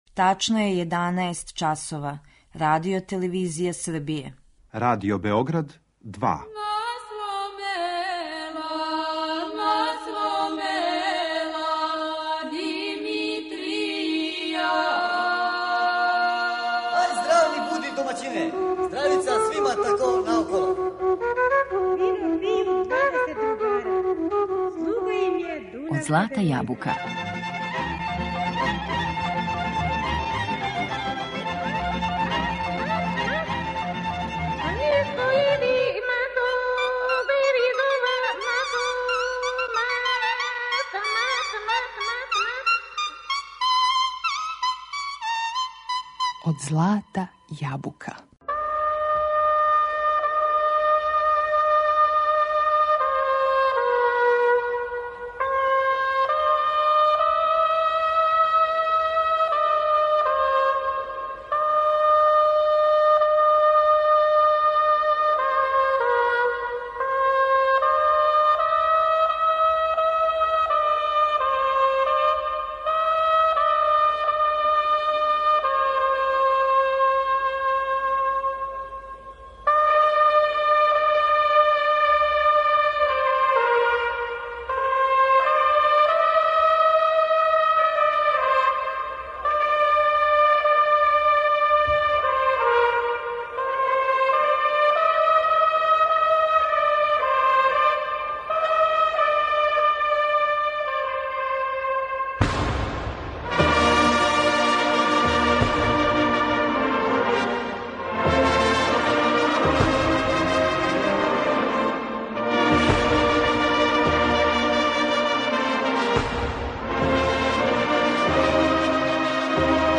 Уживаћемо и у снимцима народних кола нашег ансамбла Ренесанс.